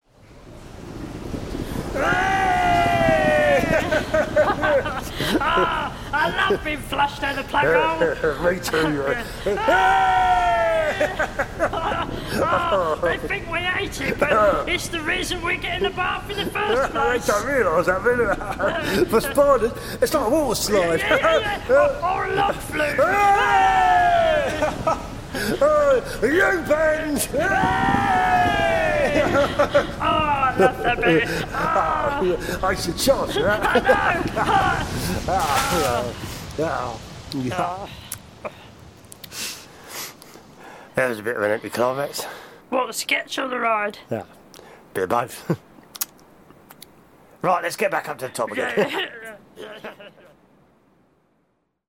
Diane Morgan and Joe Wilkinson (aka Two Episodes of Mash) combine the mundane with the surreal in their brilliant comedy sketch show. In the next episode, they demystify what spiders do for entertainment.